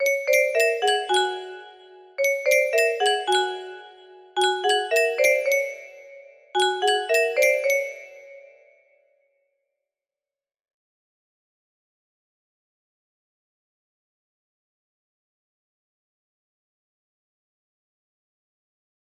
Sound Effect music box melody